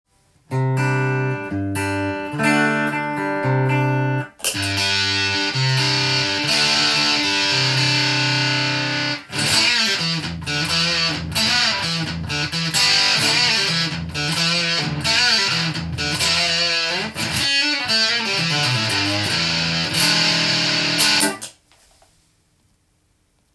レトロな音の為　思わずレニークラビッツを弾いてしまいました。
潰れた感じは、ファズらしさが出ています。
ロトサウンドの方が　古臭く　あまり歪まない印象でした。